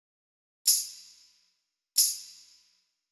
Perc.wav